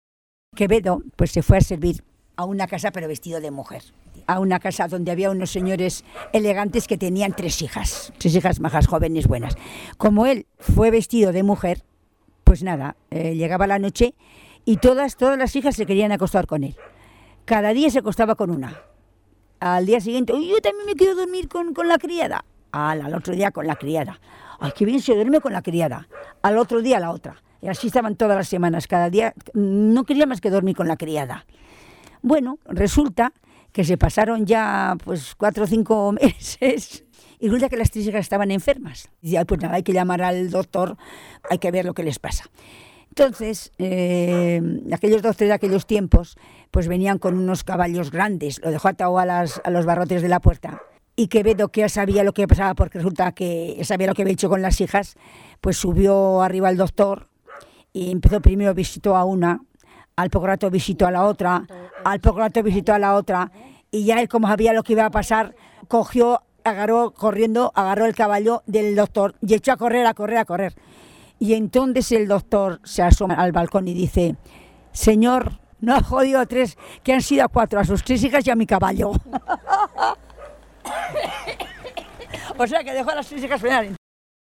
Clasificación: Cuentos
Lugar y fecha de recogida: El Redal, 17 de julio de 2003